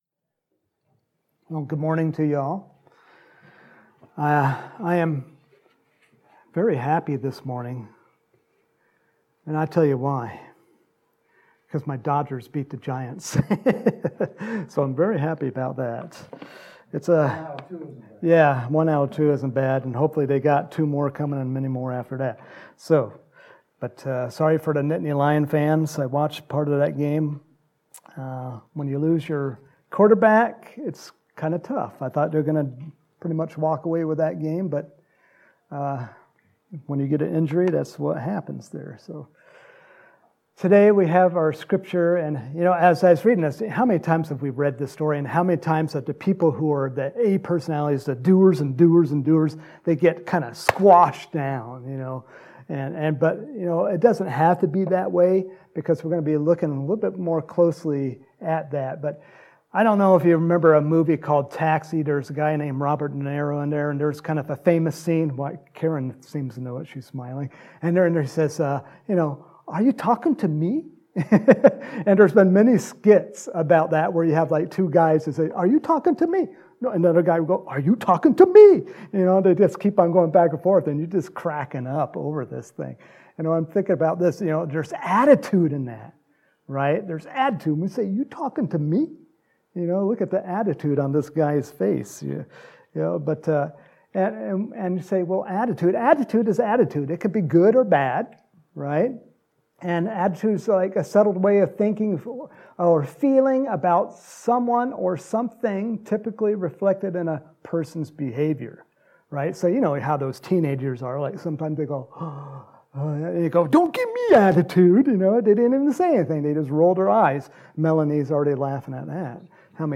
Home › Sermons › October 10, 2021